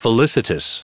Prononciation du mot felicitous en anglais (fichier audio)
Prononciation du mot : felicitous